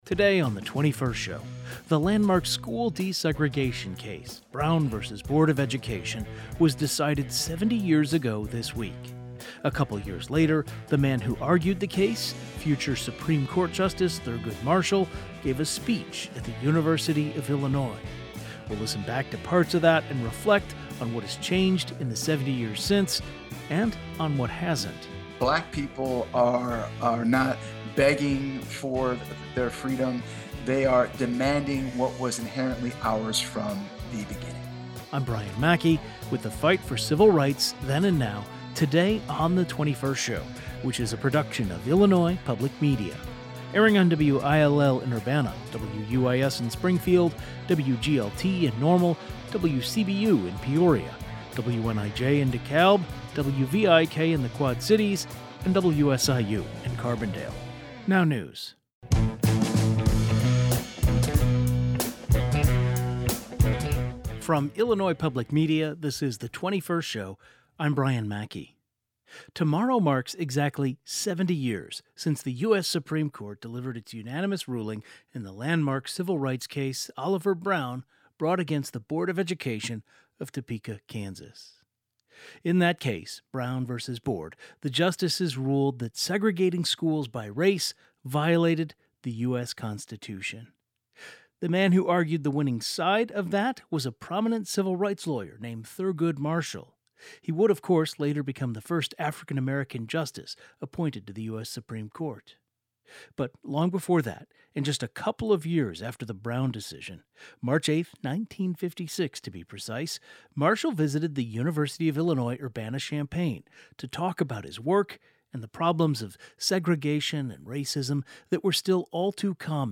In March 1956, Thurgood Marshall visited the University of Illinois Urbana-Champaign. A modern-day civil rights leader and Black Studies professor reacted to Marshall's speech.